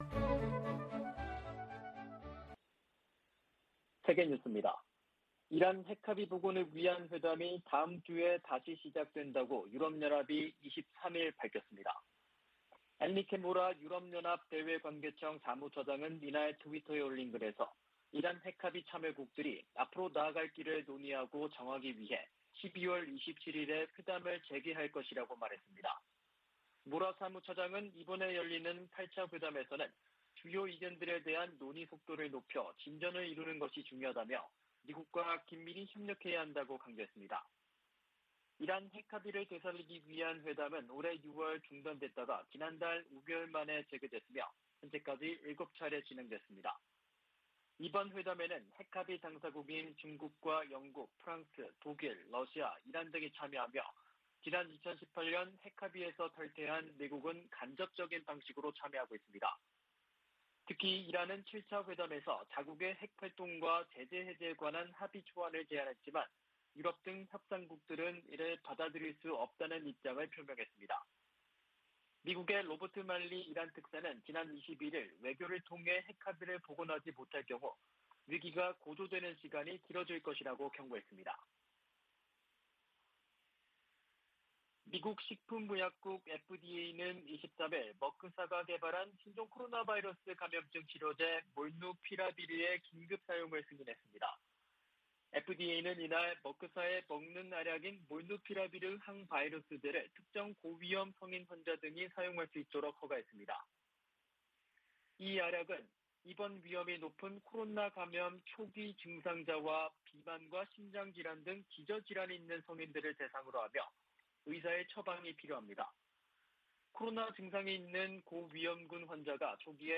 VOA 한국어 아침 뉴스 프로그램 '워싱턴 뉴스 광장' 2021년 12월 24일 방송입니다. 미 의회가 올해 처리한 한반도 외교안보 관련안건은 단 한 건이며, 나머지는 내년으로 이월될 예정입니다. 미국과 한국의 연합군사훈련 시기를 포함한 모든 결정은 양국 간 합의로 이뤄질 것이라고 미 국방부가 밝혔습니다. 올해 국제사회의 대북 지원 모금 실적이 지난 10년 새 최저 수준으로 나타났습니다.